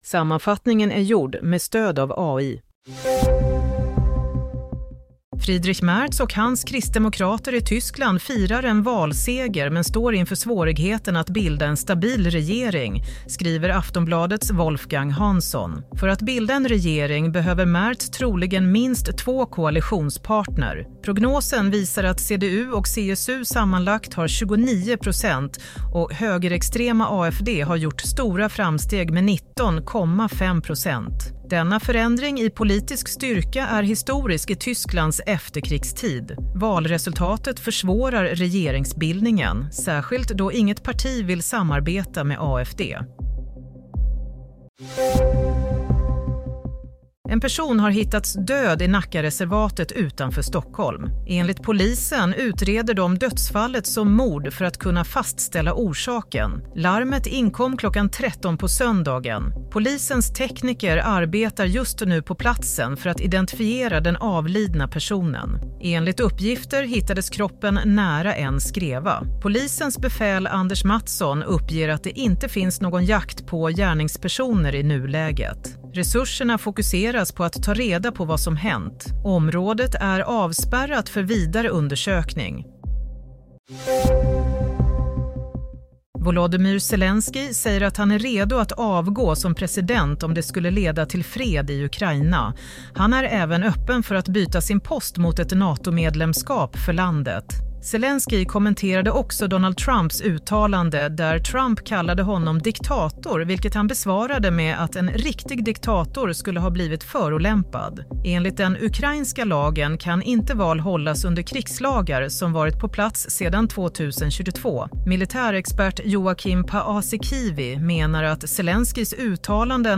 Nyhetssammanfattning - 23 februari 22.00